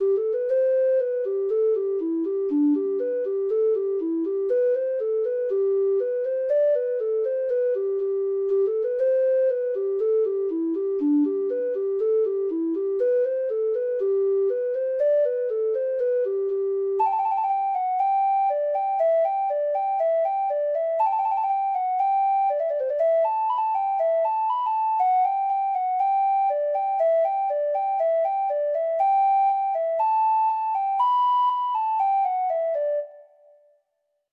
Traditional Music of unknown author.
Reels
Irish